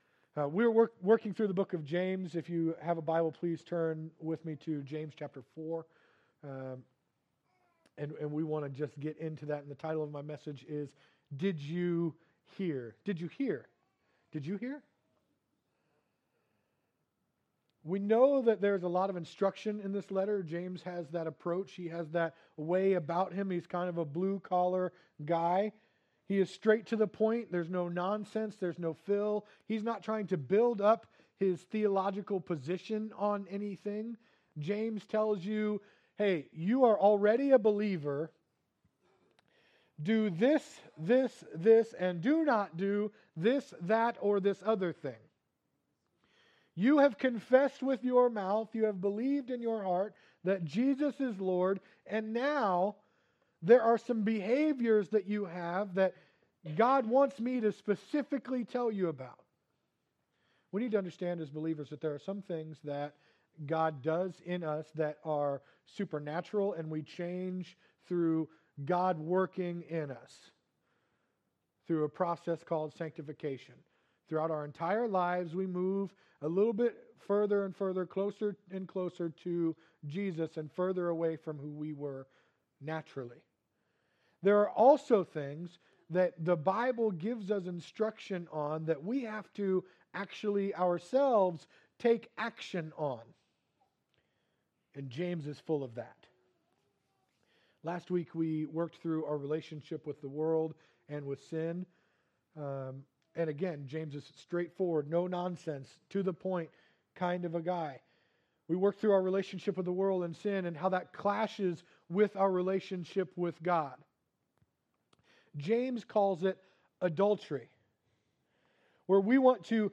Sermons | Calvary Foursquare Church